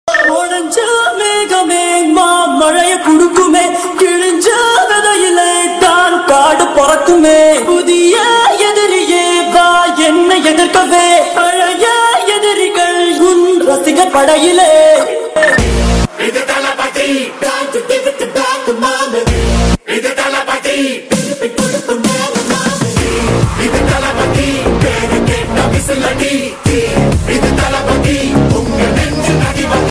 Enjoy powerful Tamil mass BGM tone for calls